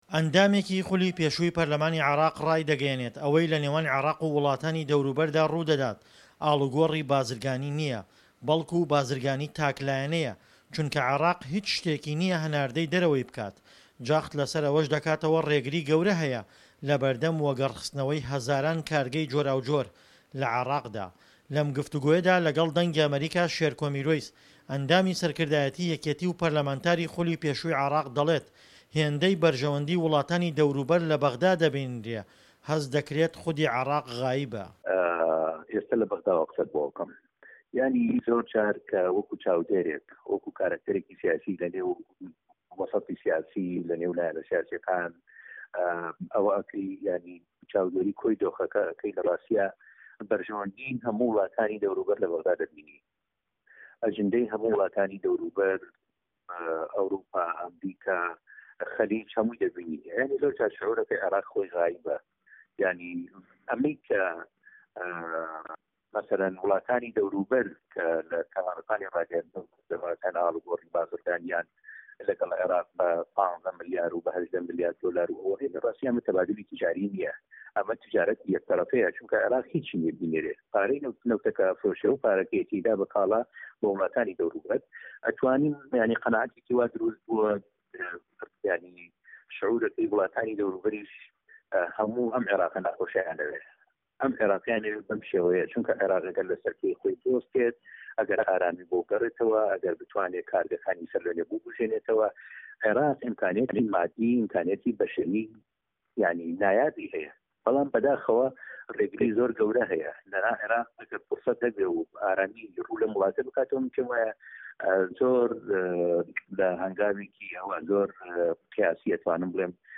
لەم گفتووگۆیەدا لەگەڵ دەنگی ئەمەریکا، شێرکۆ میروەیس ئەندامی سەرکردایەتی یەکێتی و پەرلەمانتاری خولی پێشووی عێراق، دەڵێت"هێندەی بەرژەوەندی وڵاتانی دەوروبەر لە بەغدا دەبینرێ، هێندەی ئەجێندای وڵاتانی دەوروبەر بوونی هەیە، هەست دەکرێت خودی عێراق غائیبە."